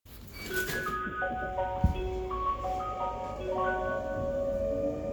・01形車内チャイム
駅発車時
熊電標準の車内チャイムを使用しています。